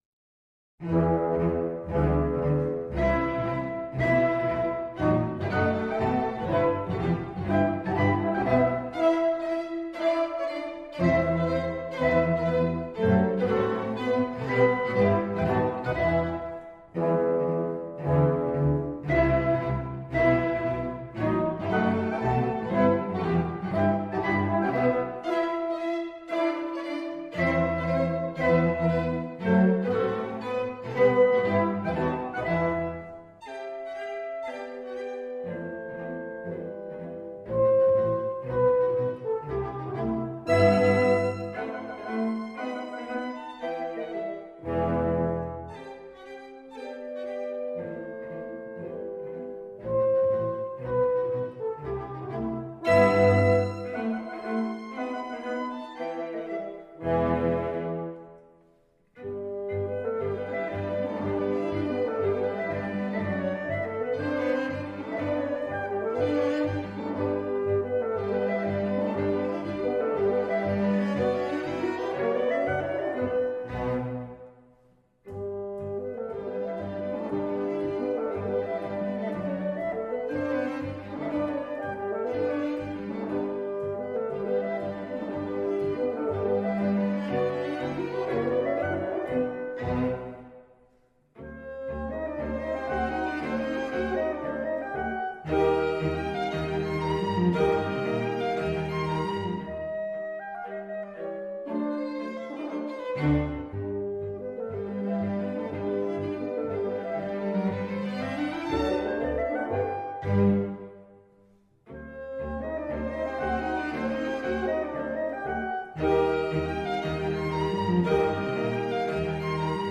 Violin, Viola, Cello, Bass, Clarinet, Horn & Bassoon